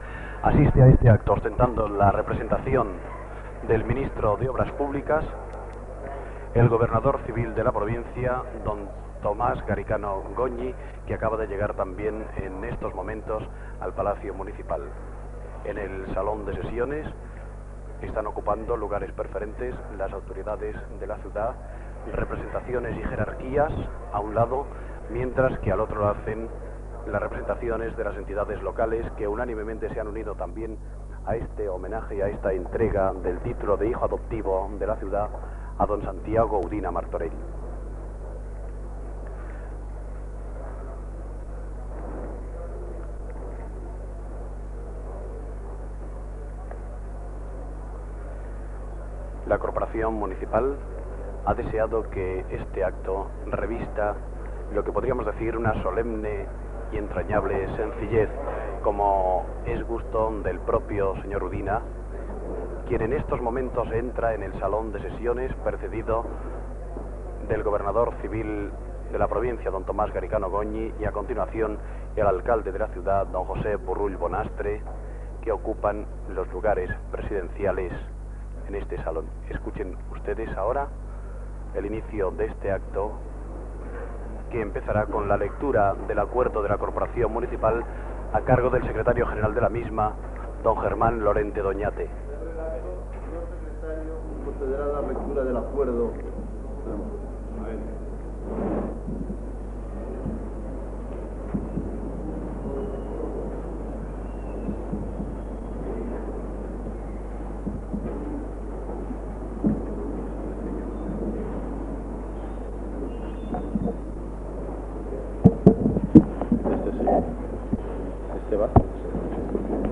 Retorn de la connexió als estudis Gènere radiofònic Informatiu